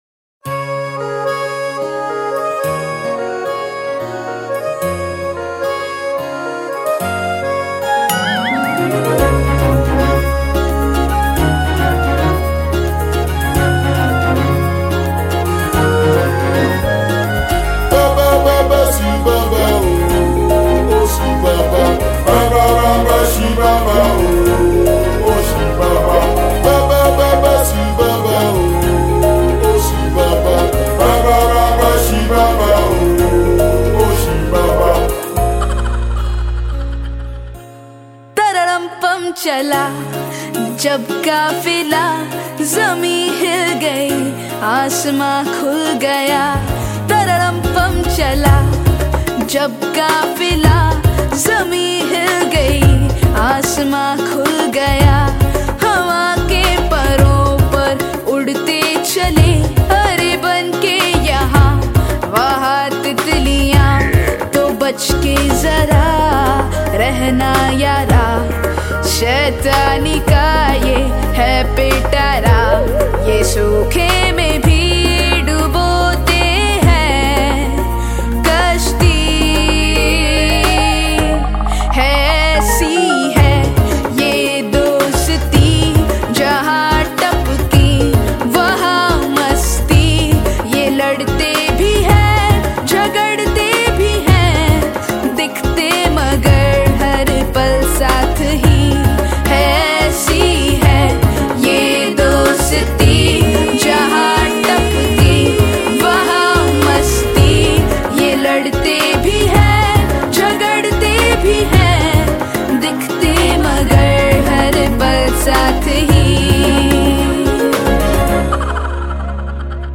Bollywood Mp3 Music 2016